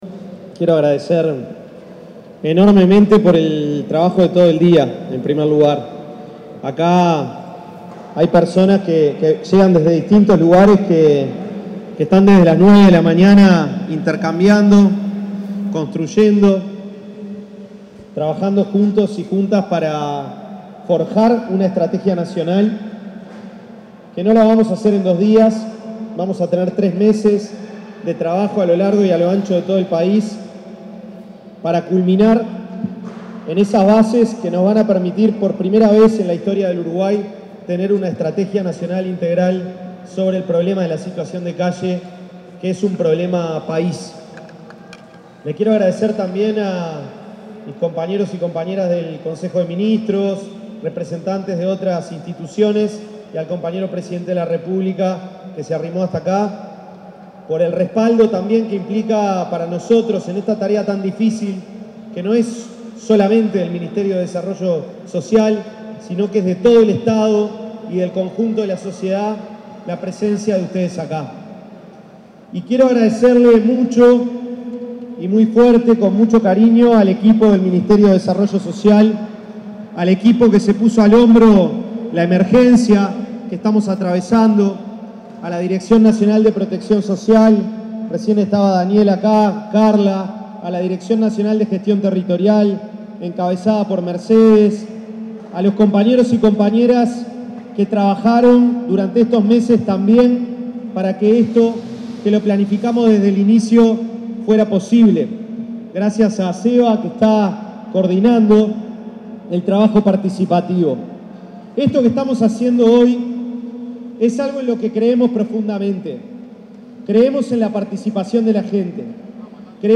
Palabras del ministro de Desarrollo Social, Gonzalo Civila
Durante el primer encuentro de coordinación para el abordaje de situaciones de calle, se expresó el ministro de Desarrollo Social, Gonzalo Civila.
oratoria.mp3